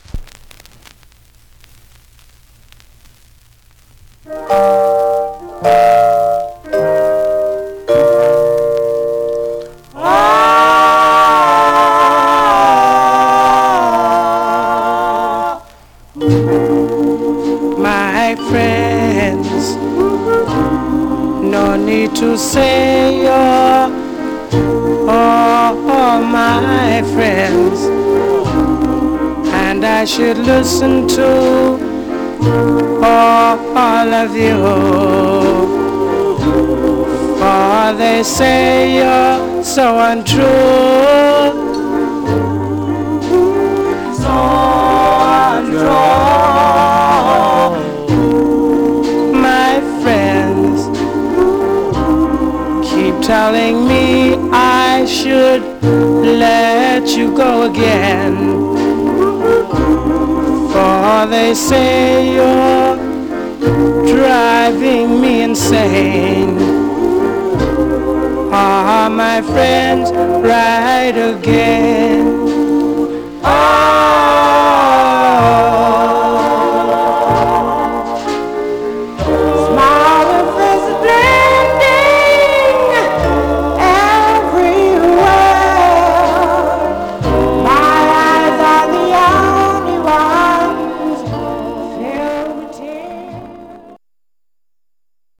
Mono
Some surface noise/wear
Male Black Groups